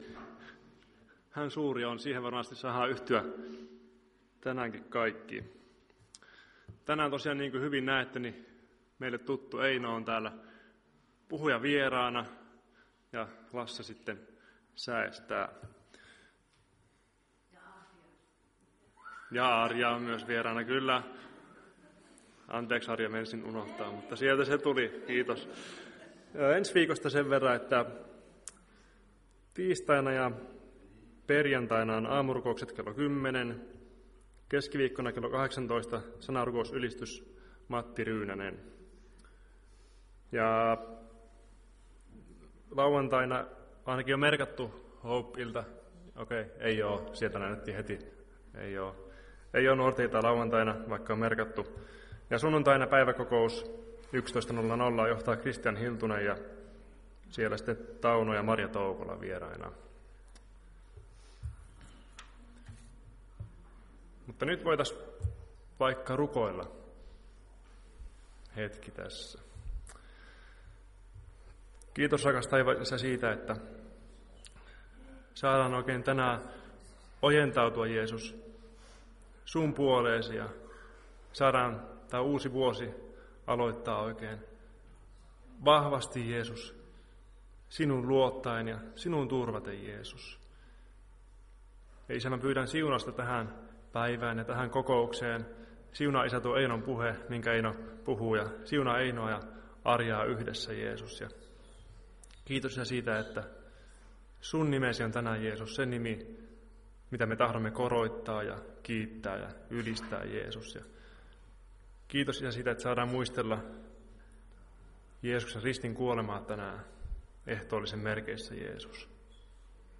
Ehtoolliskokous 5.1.2025